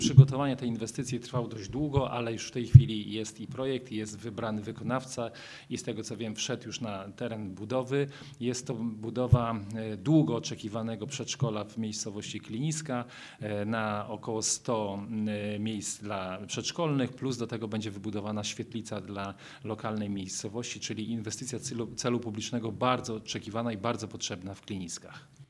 O potrzebie budowy obiektu mówi wiceburmistrz Goleniowa Tomasz Banach: